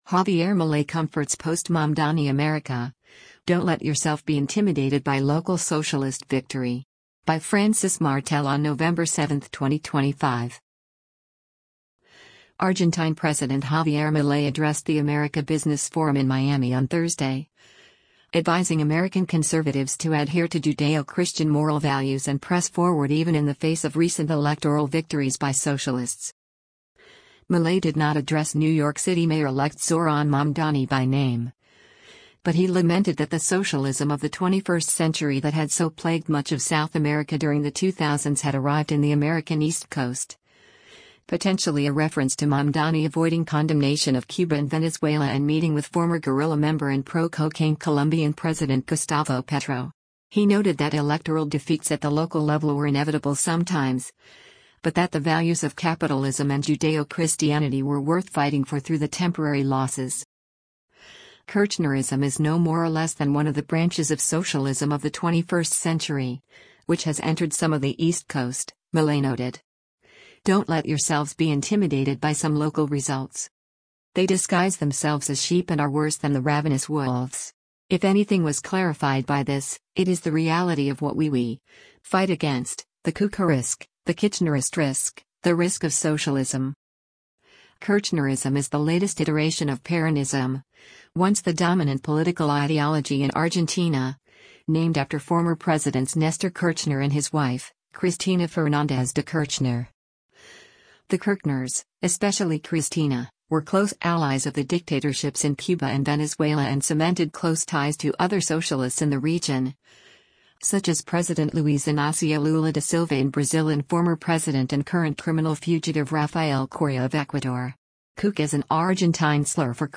Argentine President Javier Milei addressed the America Business Forum in Miami on Thursday, advising American conservatives to adhere to Judeo-Christian moral values and press forward even in the face of recent electoral victories by socialists.